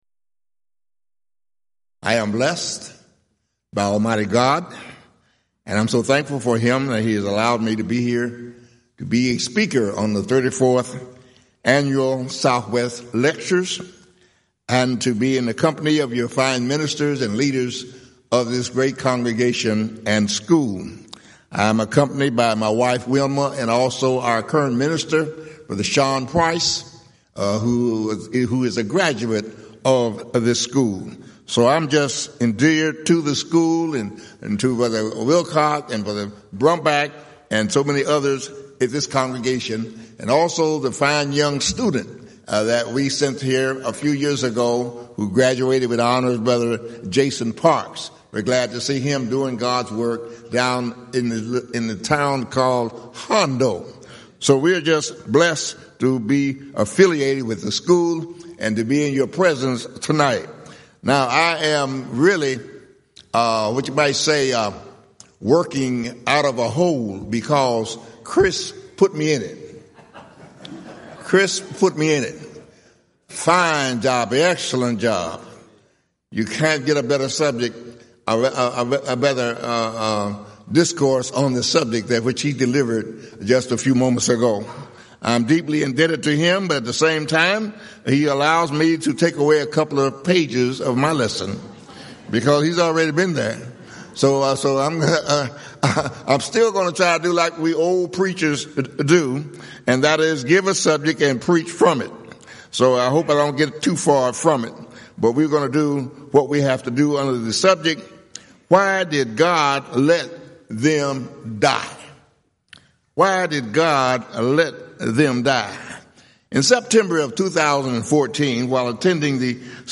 Event: 34th Annual Southwest Lectures
lecture